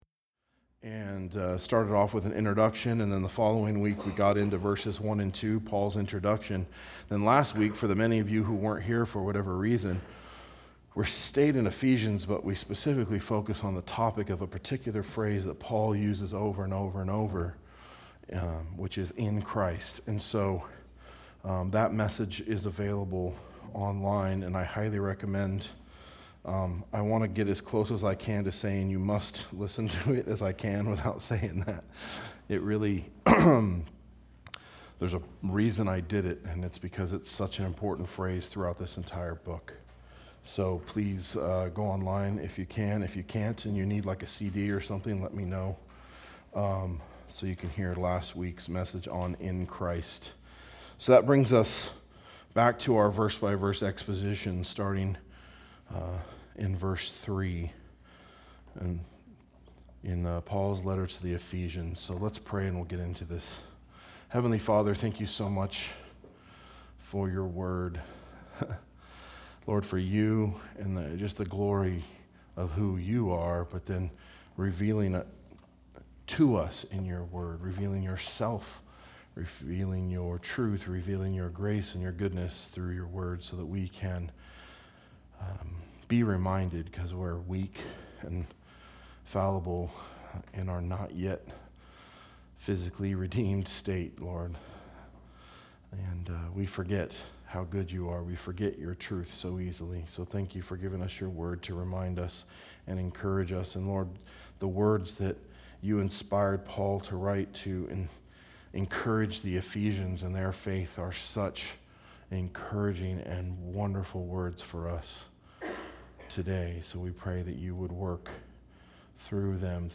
A message from the series "Ephesians." Exposition of Ephesians 1:3-6